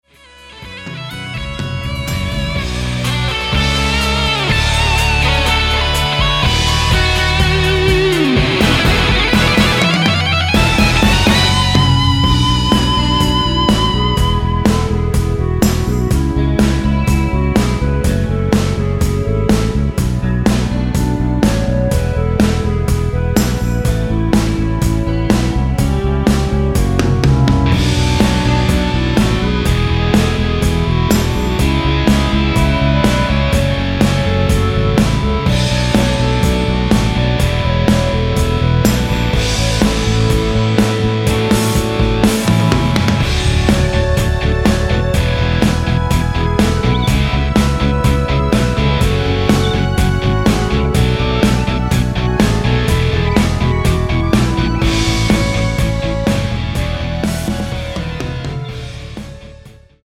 원키에서(-2)내린 멜로디 포함된 MR입니다.(미리듣기 참조)
F#m
앞부분30초, 뒷부분30초씩 편집해서 올려 드리고 있습니다.
(멜로디 MR)은 가이드 멜로디가 포함된 MR 입니다.